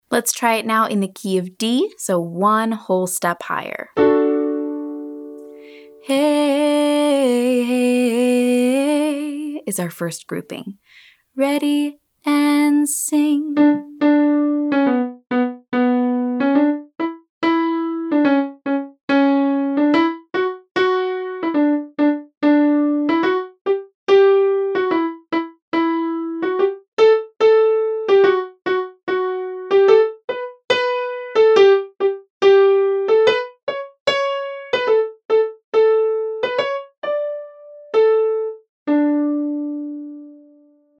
Putting it together (pulse/bend + 3-note riff)
Let’s learn this, listen & repeat style.